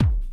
1 Harsh Realm Kick Low.wav